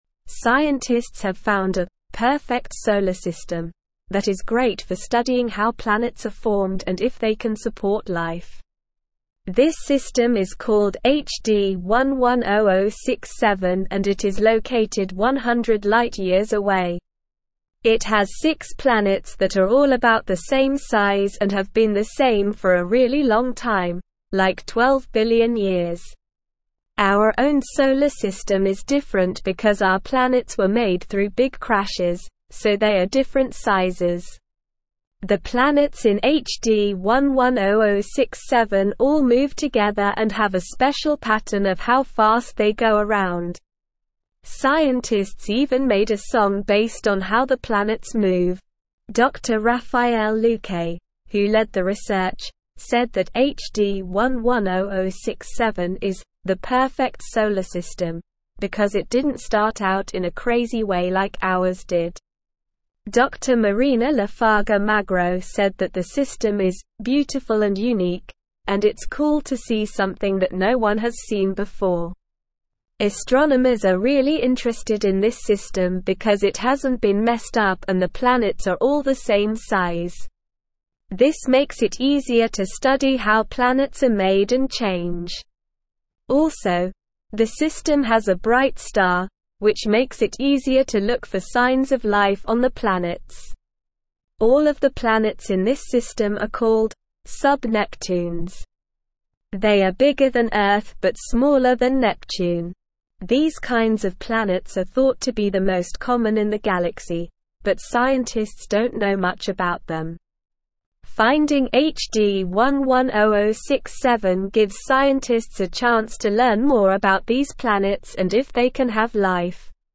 Slow
English-Newsroom-Upper-Intermediate-SLOW-Reading-Perfect-Solar-System-Potential-for-Life-and-Discovery.mp3